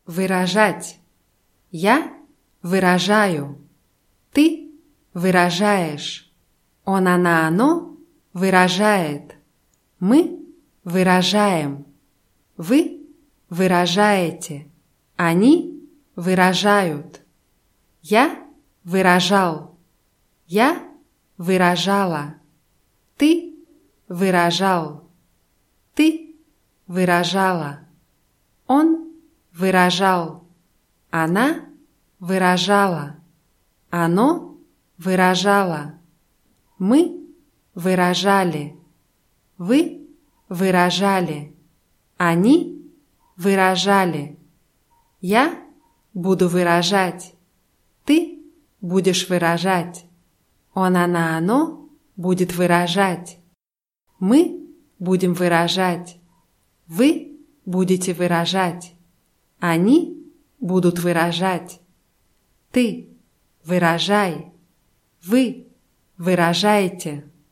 выражать [wyraschschátʲ]